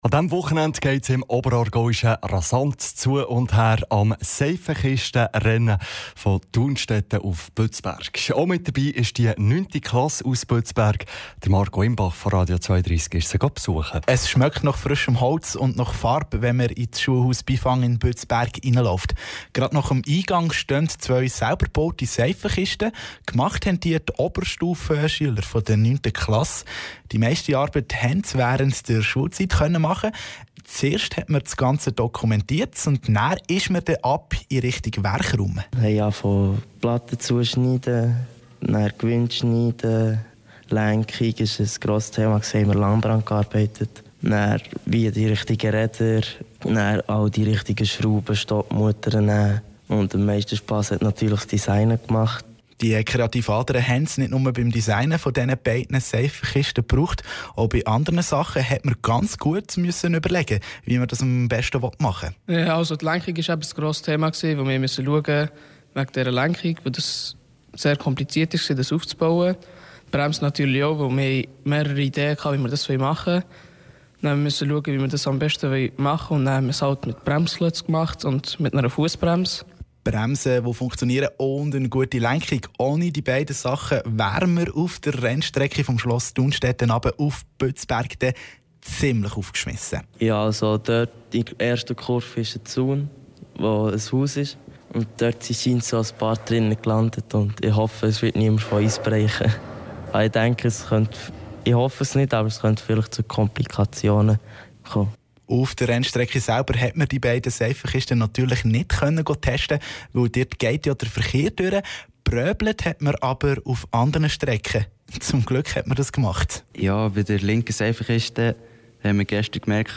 Radiobeiträge über das Seifenkistenrennen 2016, Radio 32, 20. & 21. Mai 2016